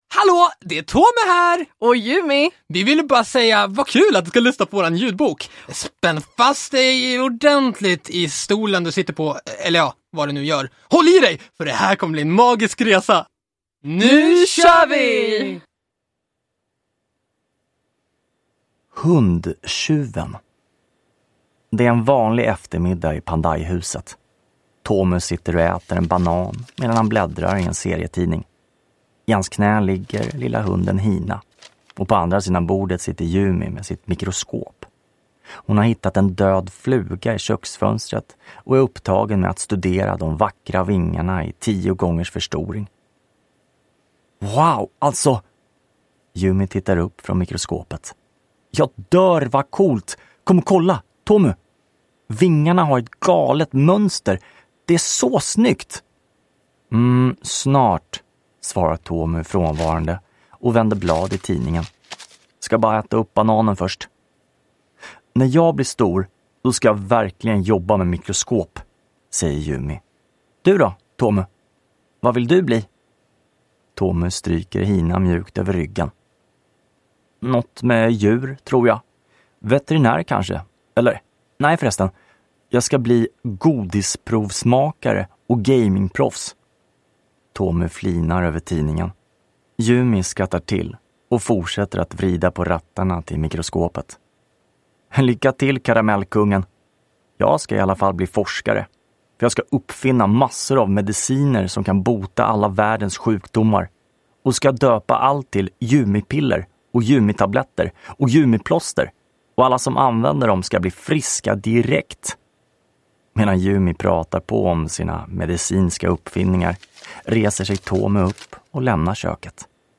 Hundtjuven – Ljudbok